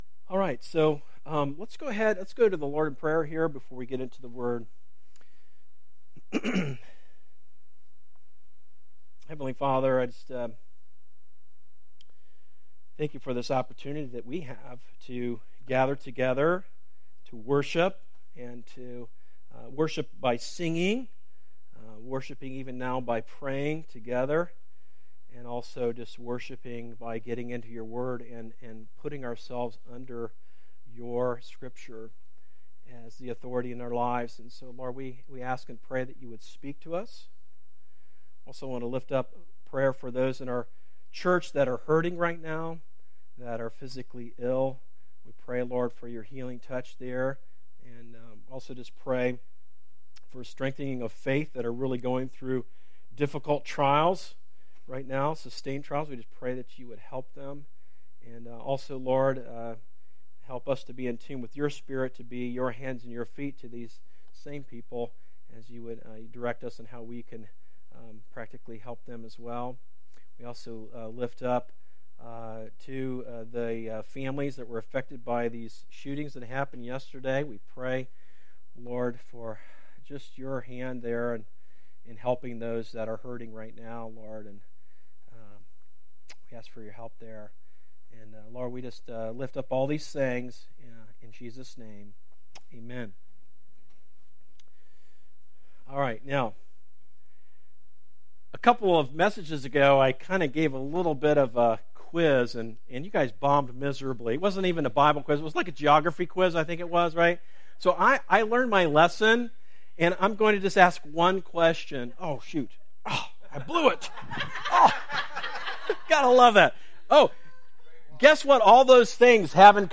A message from the series "Pray Boldly."